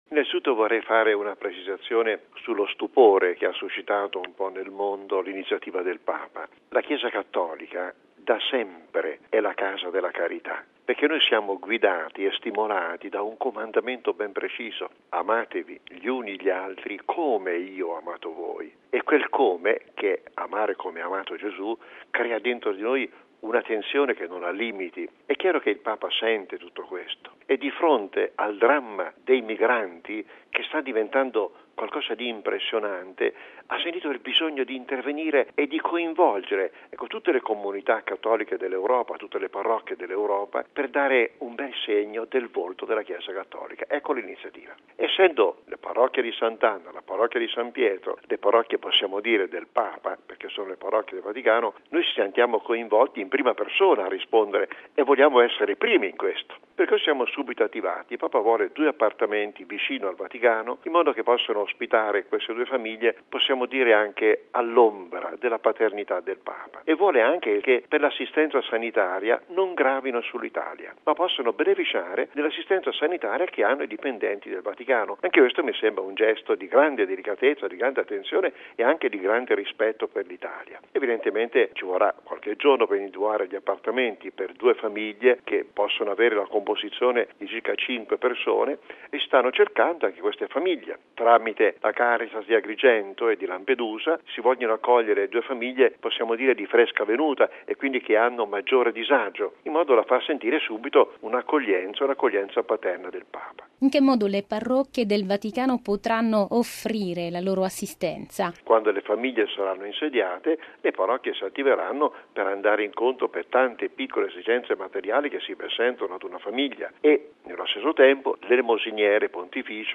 Il cardinale Angelo Comastri, vicario generale del Papa per lo Stato della Città del vaticano, ne parla al microfono